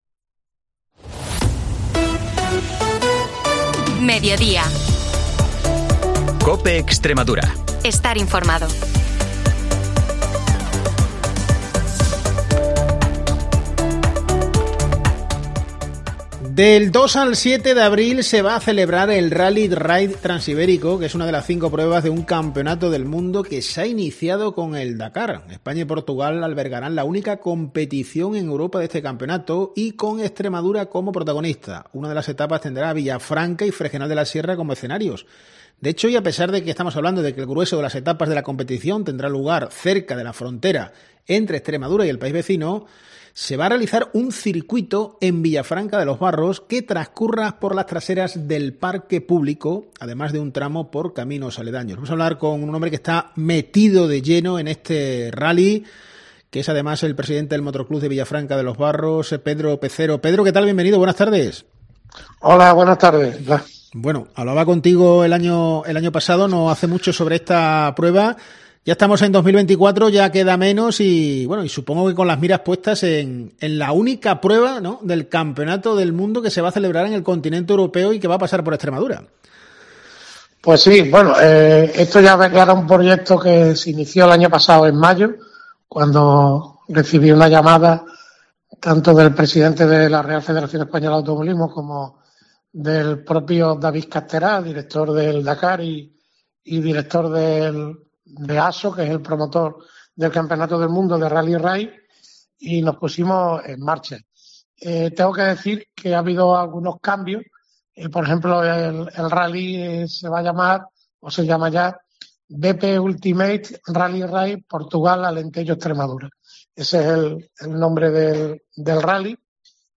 En COPE hemos hablado con